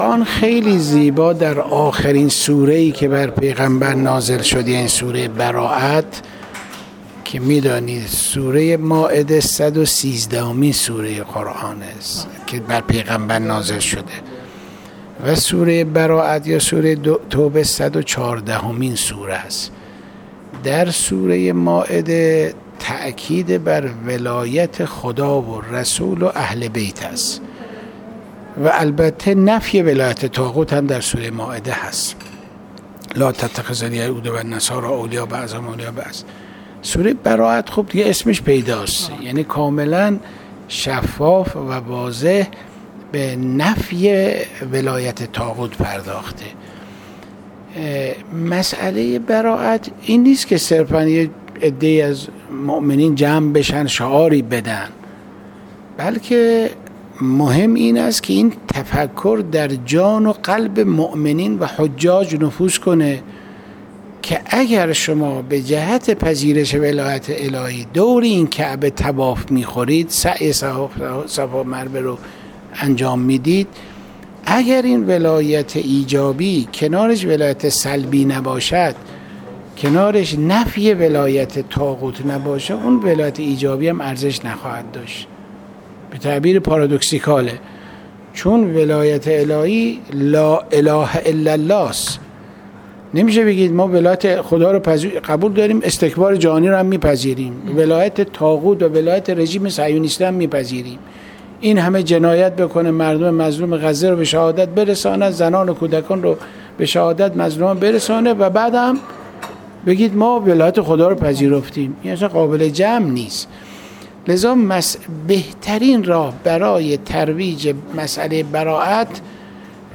حجت‌الاسلام والمسلمین عبدالحسین خسروپناه، دبیر شورای عالی انقلاب فرهنگی در گفت‌وگو با ایکنا، با توجه به تأکید مقام معظم رهبری بر تبلیغ منطق قرآنی برائت و نقش حجاج در این امر گفت: قرآن کریم خیلی زیبا در سوره برائت به عنوان آخرین سوره‌ای که بر پیامبر(ص) نازل شده است به موضوع برائت اشاره می‌کند.